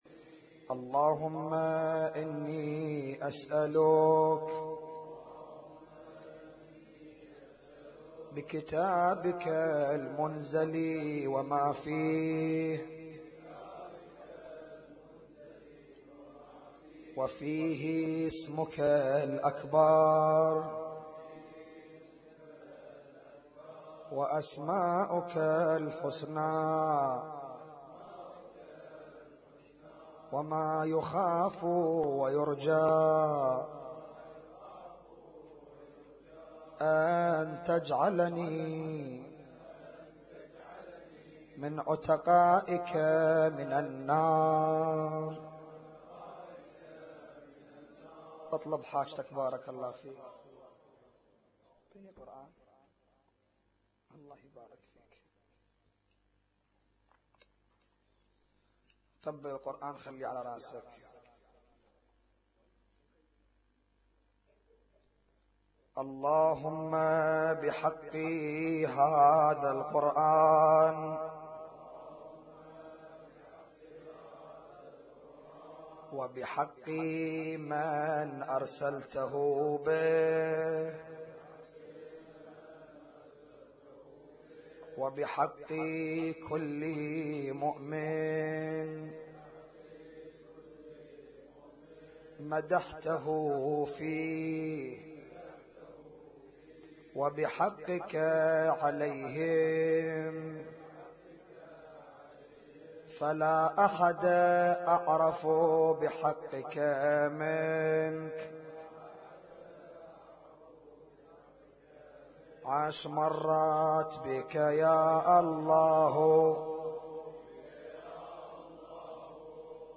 اسم النشيد:: الاحياء ليلة 23 من رمضان 1438
اسم التصنيف: المـكتبة الصــوتيه >> الادعية >> ادعية ليالي القدر